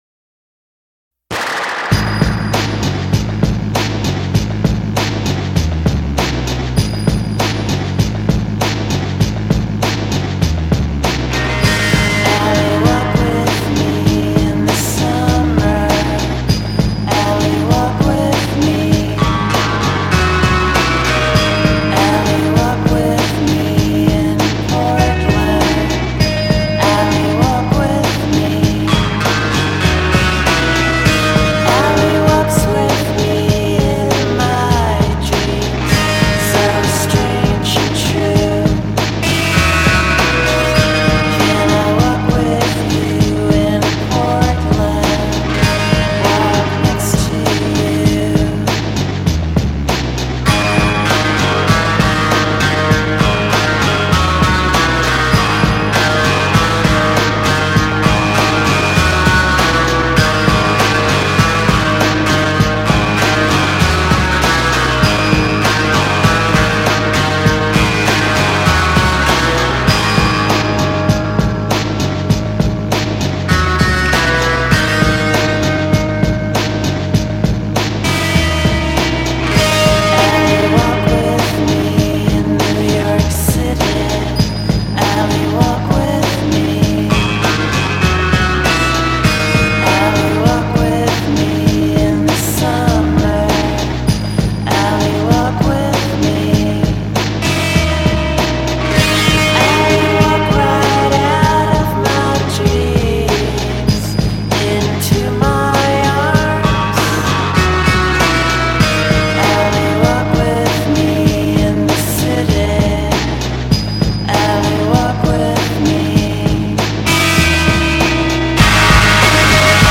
Distortion drenched music straight from Denmark.